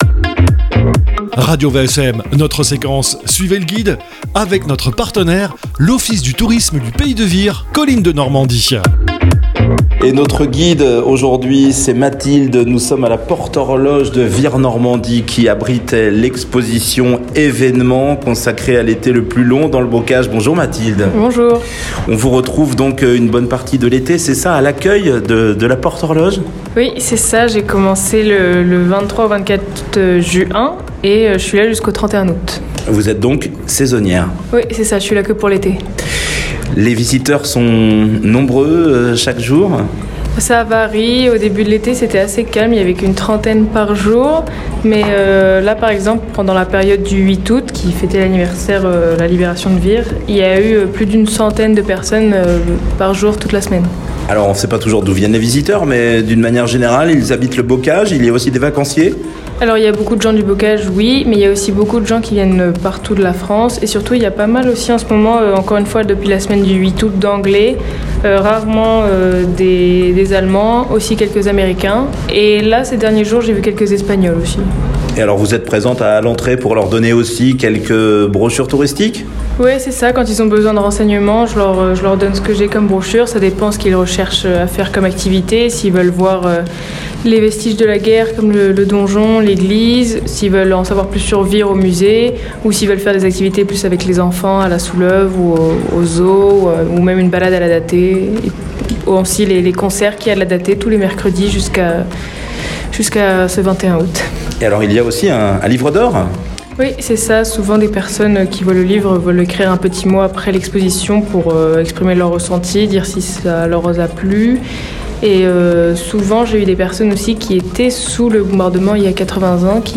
Suivez Le Guide - Interview